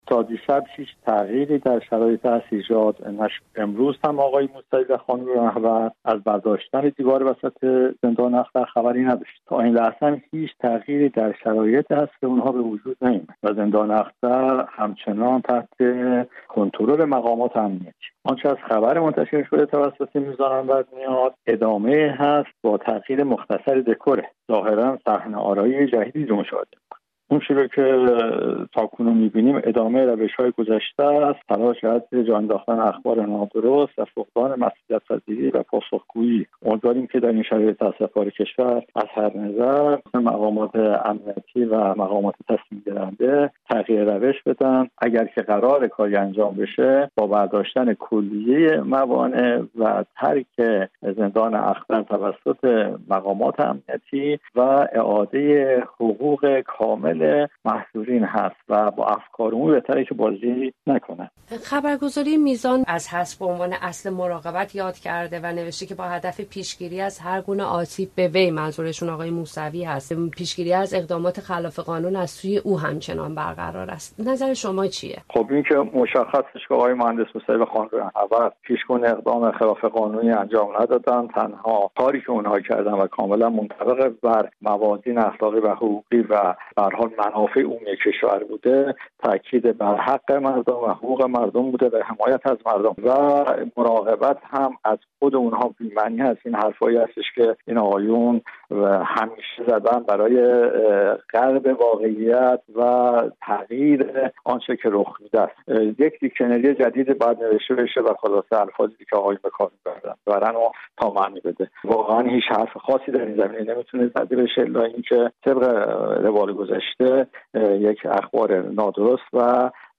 در گفت‌وگو